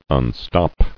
[un·stop]